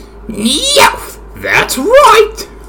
Cries
MEOWTH.mp3